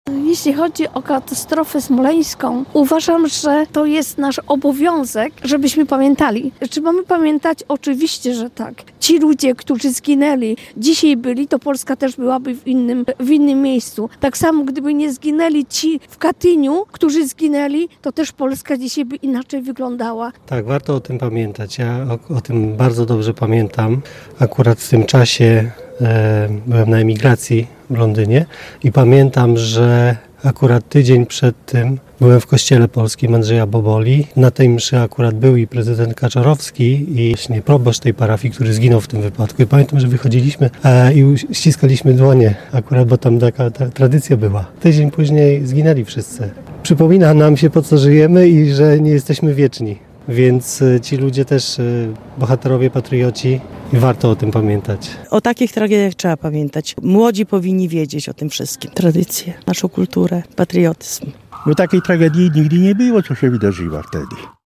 Mimo upływu czasu mieszkańcy regionu pamiętają o tragicznych zdarzeniach sprzed lat:
Mieszkancy-regionu-o-tragedii-smolenskiej.mp3